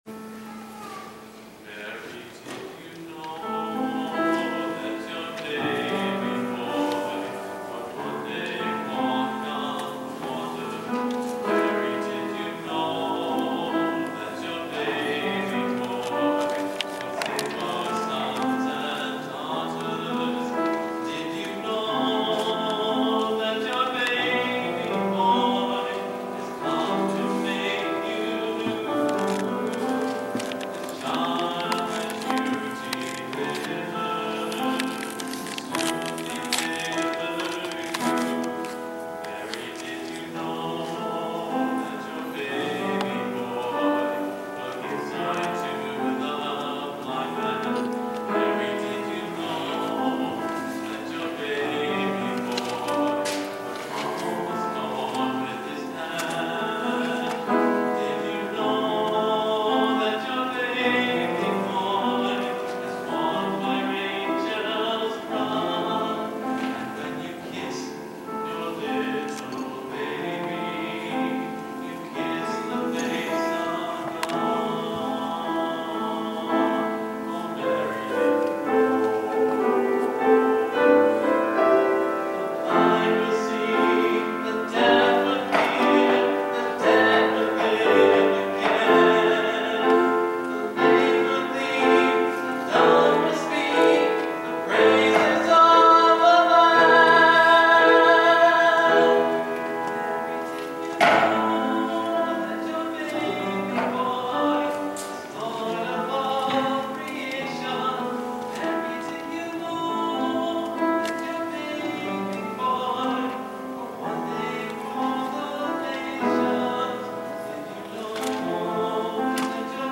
Avondale United Methodist Church, Church 2 Minutes
Soundtrack of “Mary Did You Know?” from the pageant
The pageant, an adaptation of “God’s Angels’ by Peggy Woon, was a reader’s theatre of the Christmas story, accented by congregational carol singing themed to the specific section of the pageant.